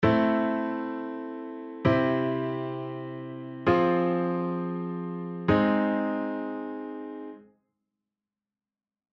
♭VIでも切なさを演出
↓の例は、VIｍ⇒Ｉ⇒IV⇒♭VI（Am⇒C⇒F⇒A♭）です。